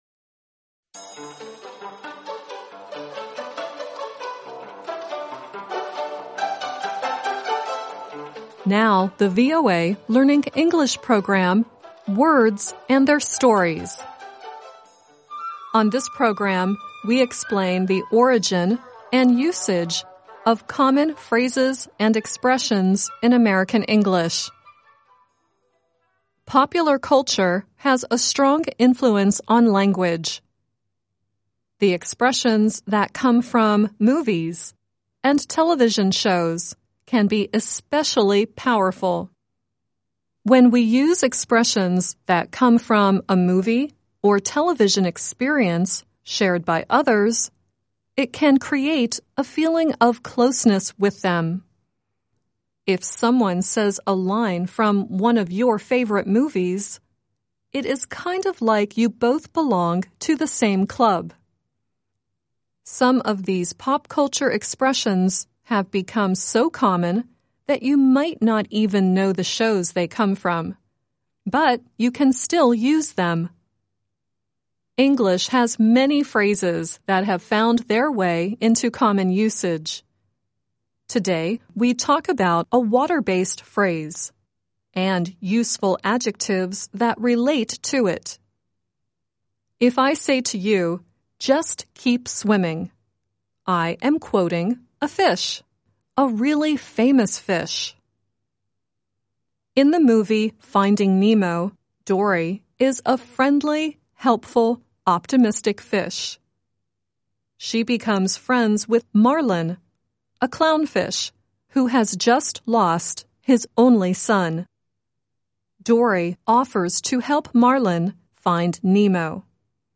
The song at the end is Bruno Mars singing “Don’t Give Up” on the children's television show Sesame Street.